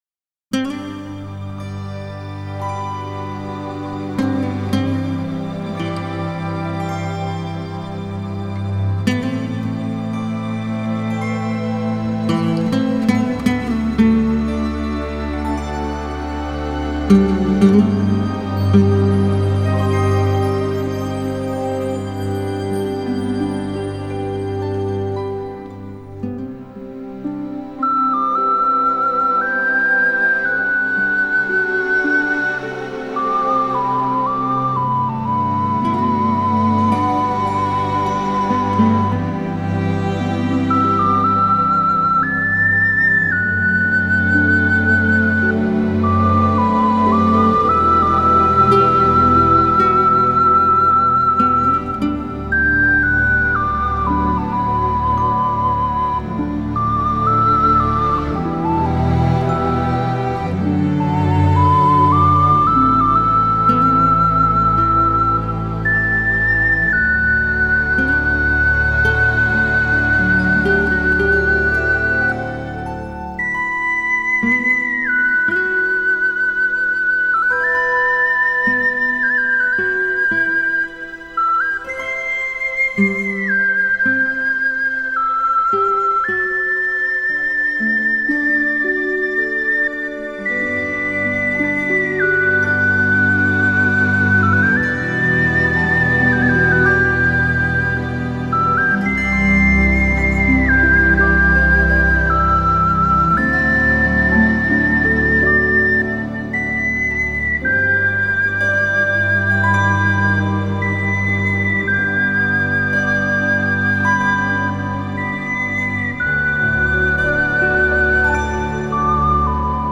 Genre: Age New.